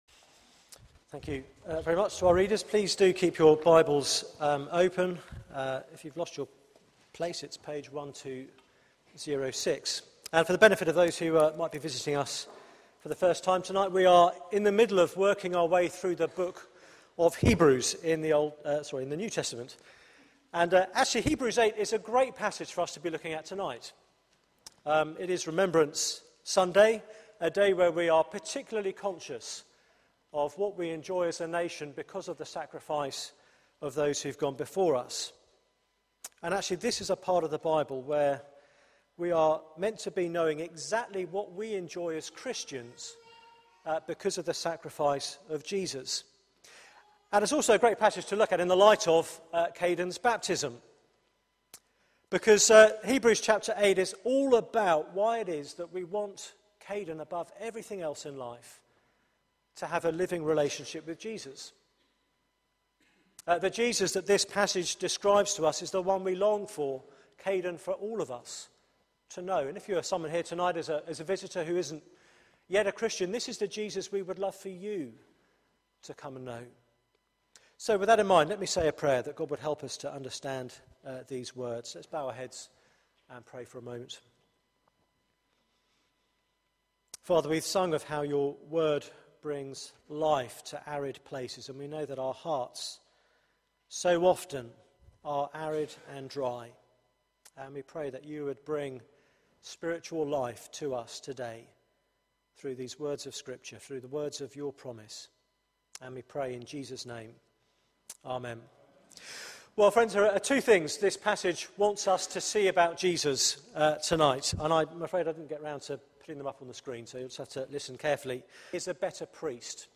Media for 6:30pm Service on Sun 10th Nov 2013 18:30 Speaker
Theme: A new covenant Sermon